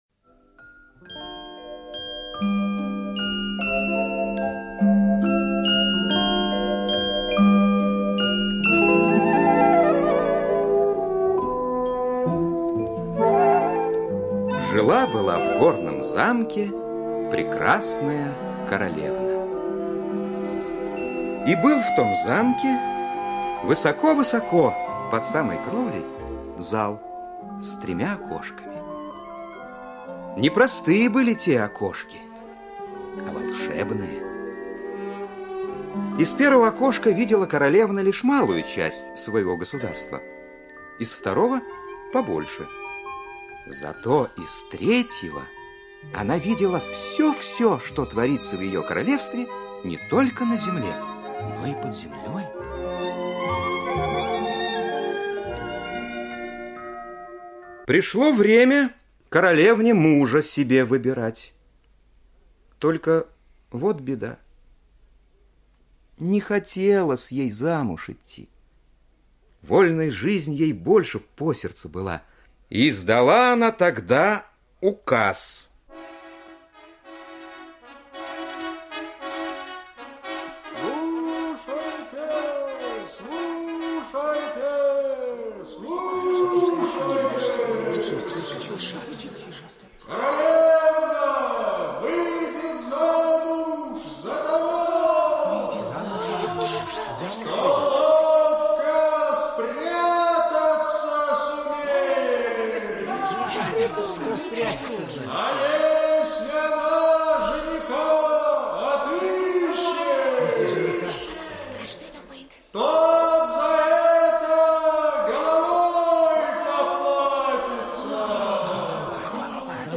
О дочери короля, которая всё видела - немецкая аудиосказка - слушать онлайн